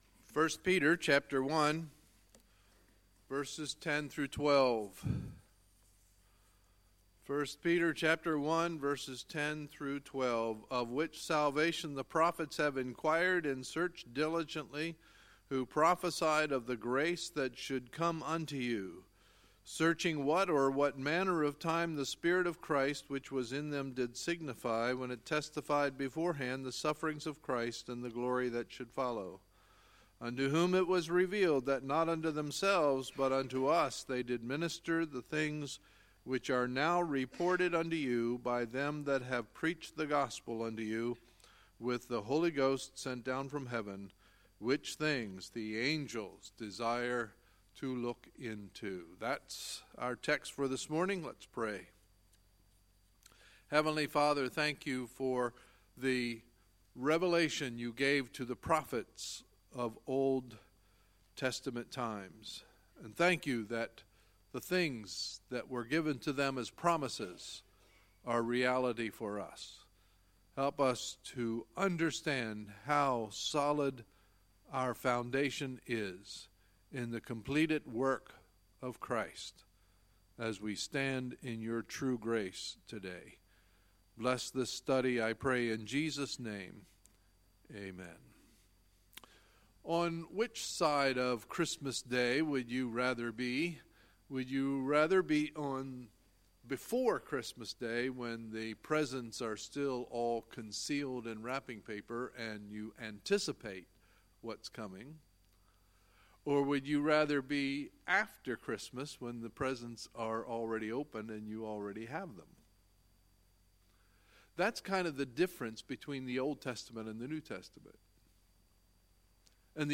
Sunday, February 4, 2018 – Sunday Morning Service